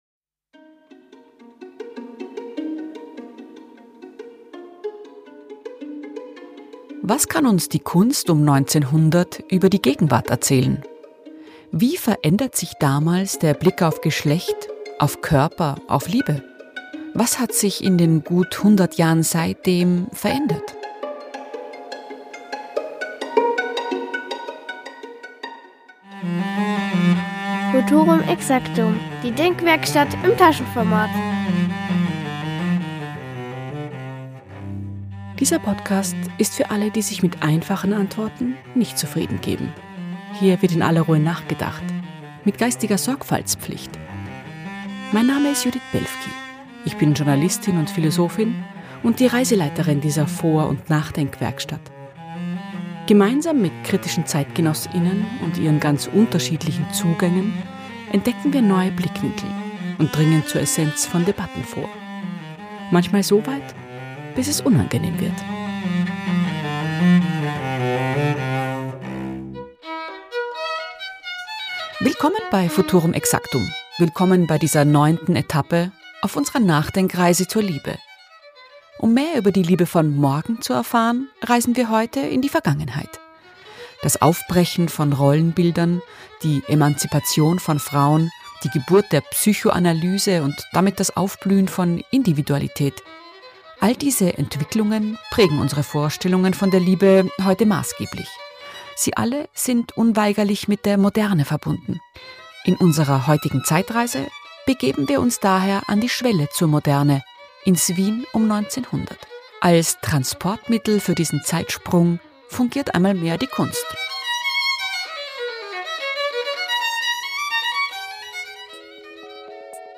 Futurum exactum begibt sich in dieser Folge auf Zeitreise ins Wien um 1900. Im Leopold Museum